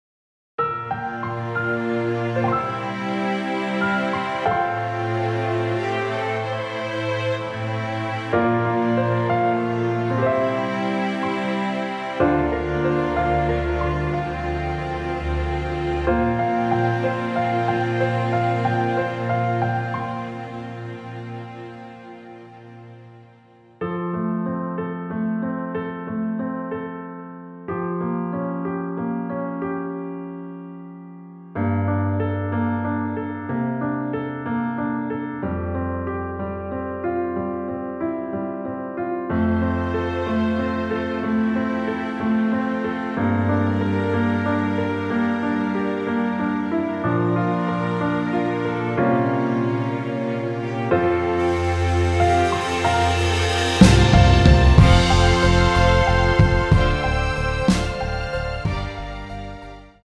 원곡보다 짧은 MR입니다.(아래 재생시간 확인)
원키에서(+4)올린 (짧은편곡)MR입니다.
앞부분30초, 뒷부분30초씩 편집해서 올려 드리고 있습니다.